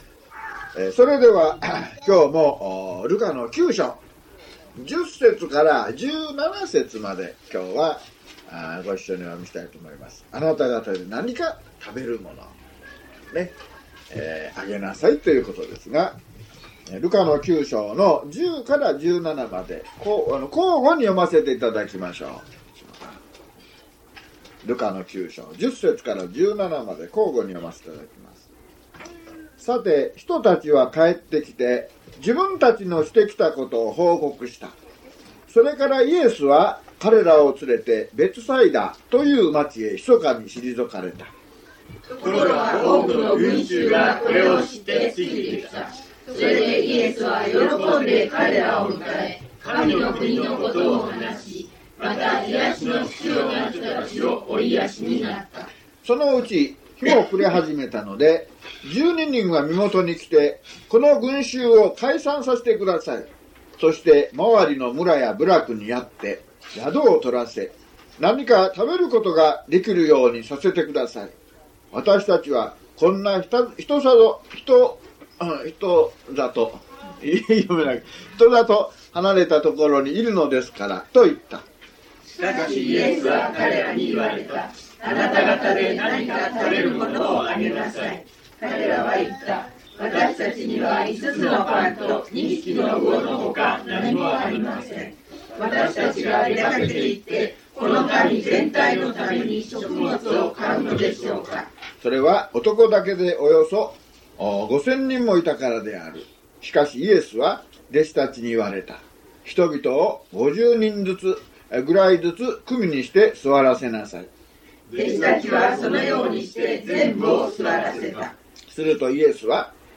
luke064mono.mp3